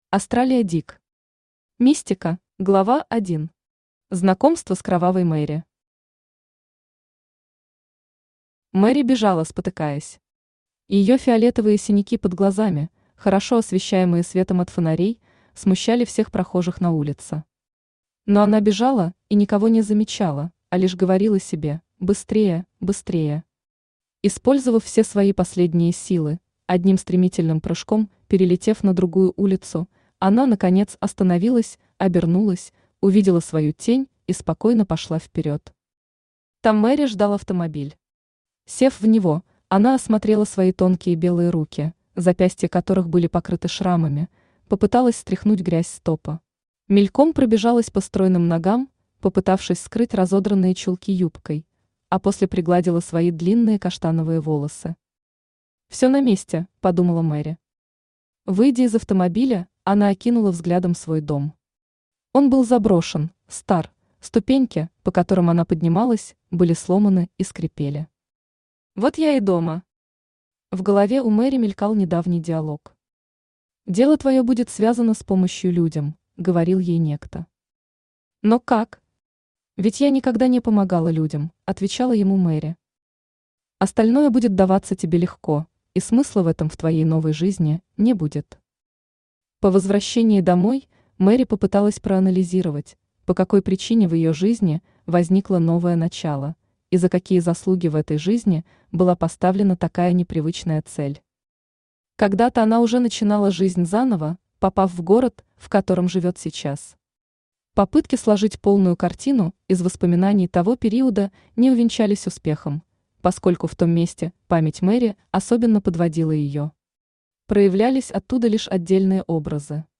Аудиокнига Мистика | Библиотека аудиокниг
Aудиокнига Мистика Автор Астралия Дик Читает аудиокнигу Авточтец ЛитРес.